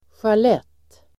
Uttal: [sjal'et:]